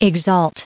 added pronounciation and merriam webster audio
1360_exalt.ogg